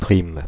Ääntäminen
Paris France: IPA: [pʁim]